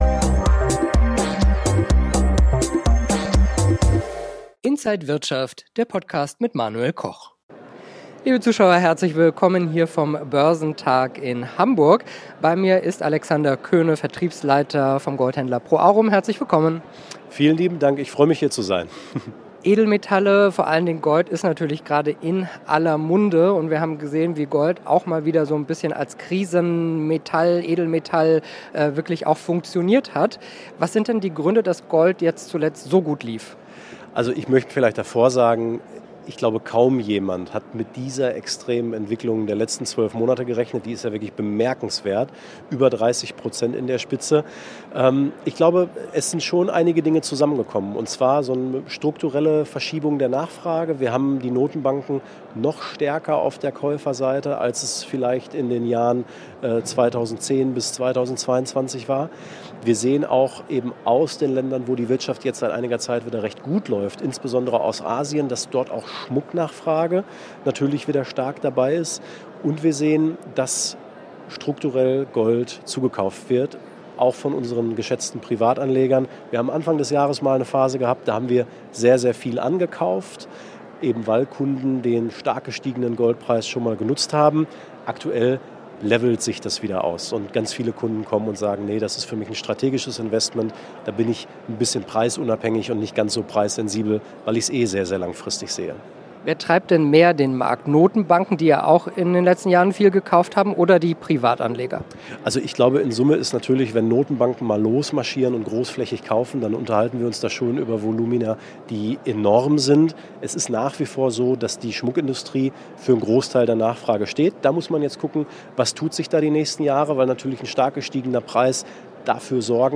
Alle Details im Interview von Inside
auf dem Börsentag Hamburg